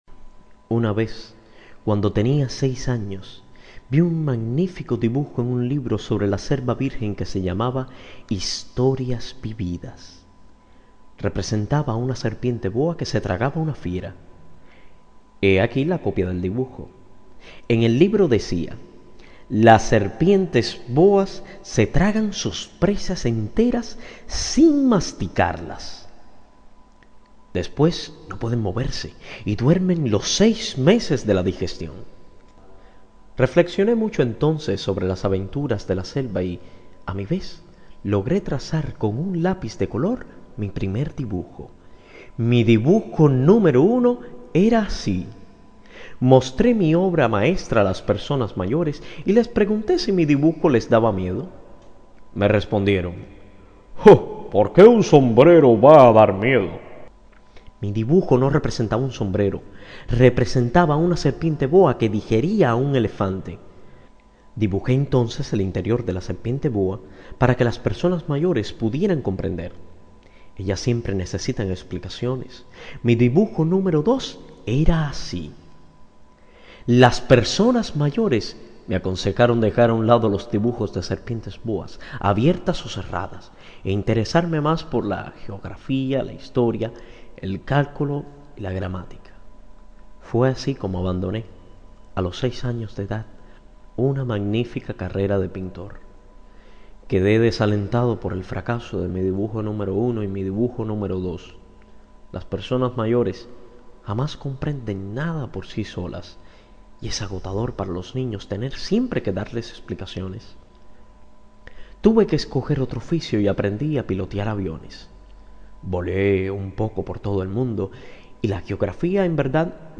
В свободном доступе я нашел испанскую аудиоверсию «El principito» с кубинским произношением (довольно отчетливым). Здесь я прикрепляю отрывок (первую главу на испанском):